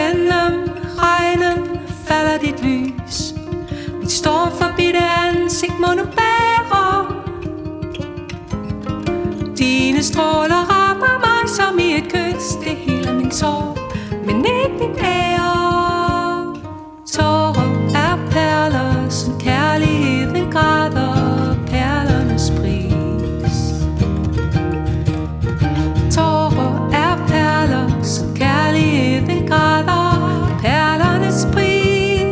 • Folk
• Indie
• Pop
• Singer/songwriter
Duo
Nordisk folk med nærvær og nerve
vokal, klaver, harmonika
guitar, vokal, produktion